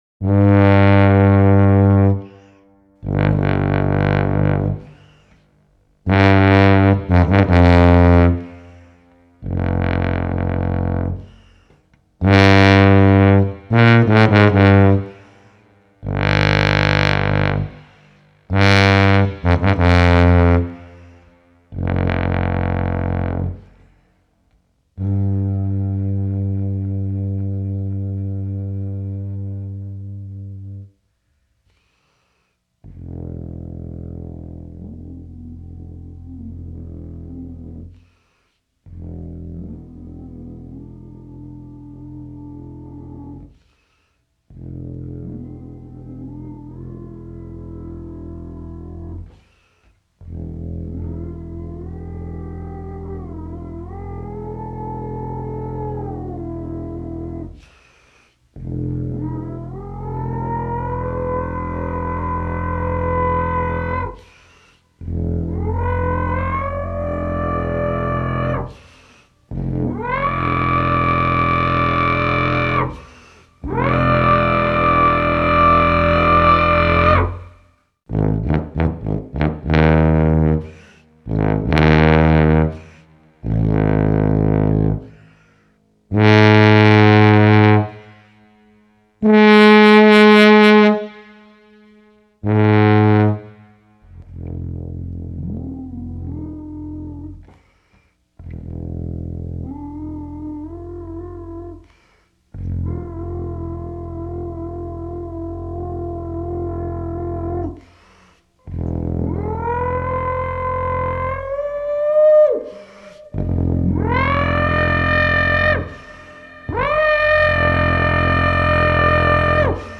А на сопровождении музыкальном - туба in B.